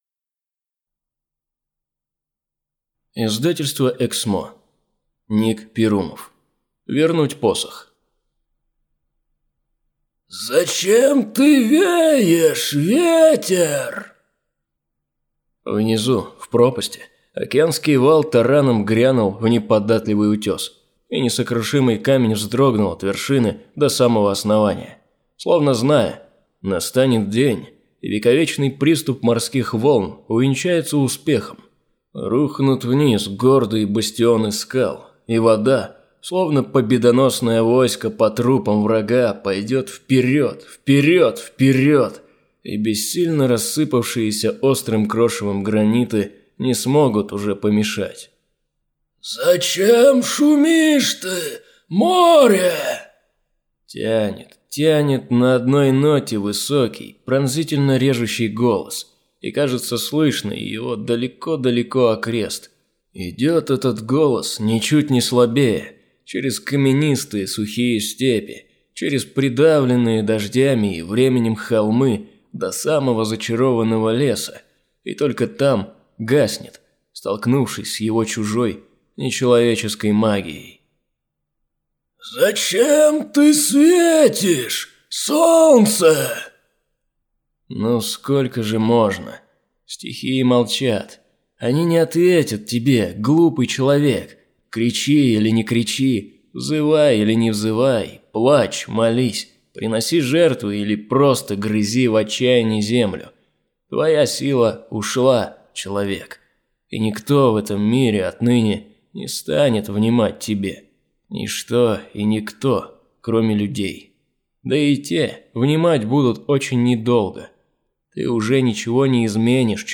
Аудиокнига Вернуть посох | Библиотека аудиокниг